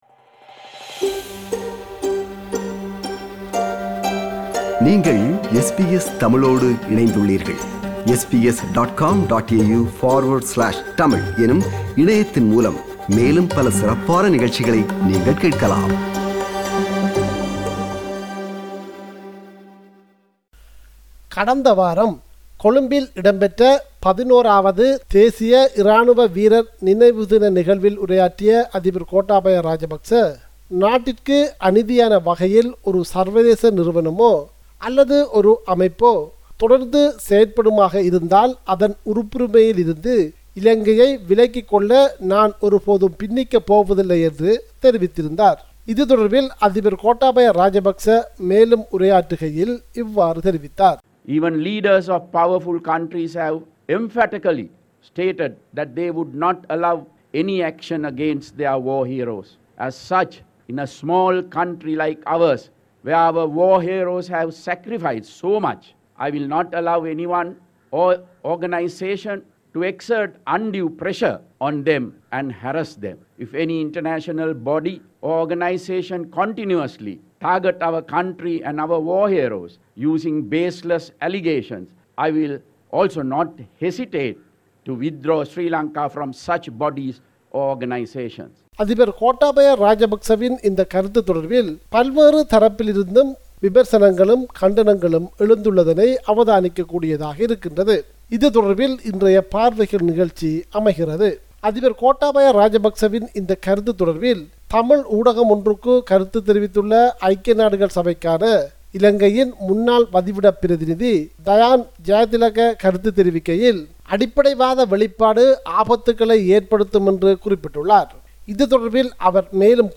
SBS Sinhala radio